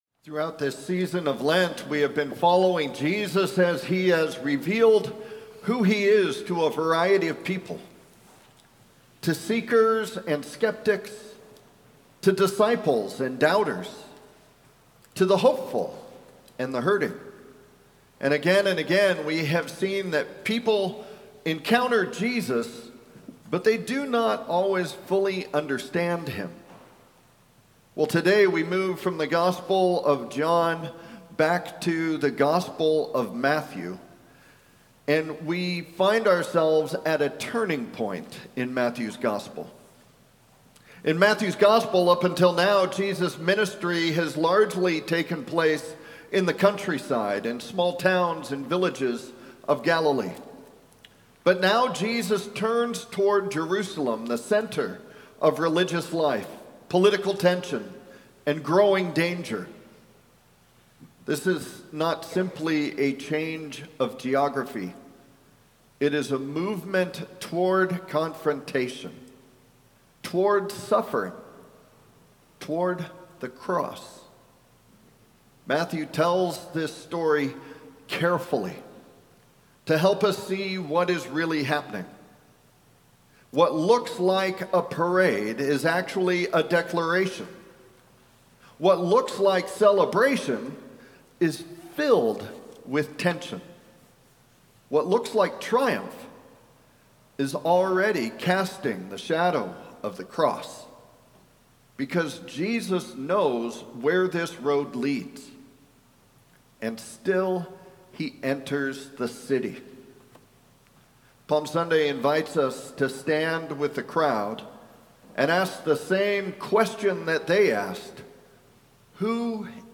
Sermon+3-29-26.mp3